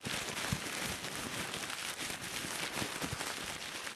crumpleLong1.ogg